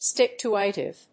/stìktúːɪṭɪv(米国英語)/